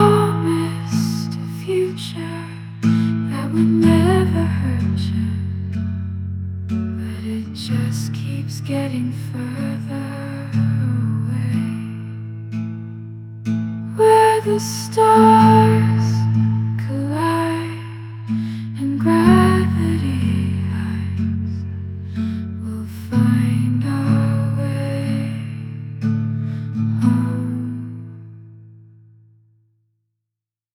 Currently experimenting on a Music EP in collaboration with AI focused on integrating my own personal music and lyrics into generative systems. A few Stripped-down sketches below